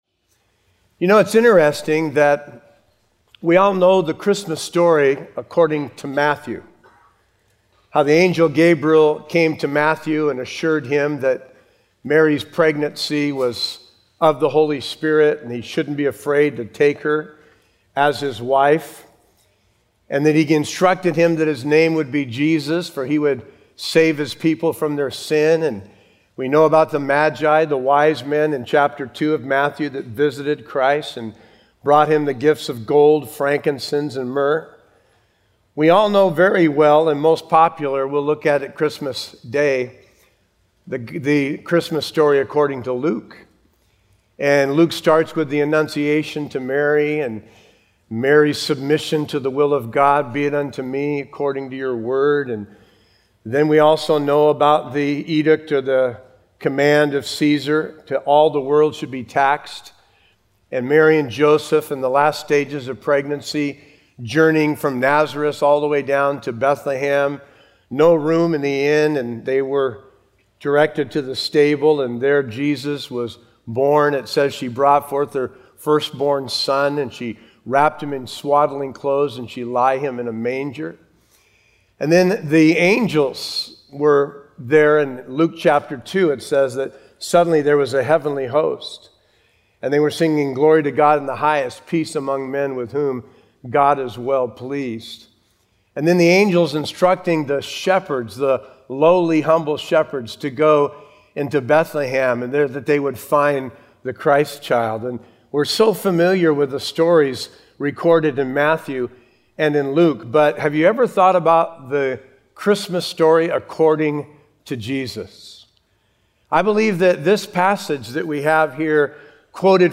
A verse-by-verse sermon through Hebrews 10:1-10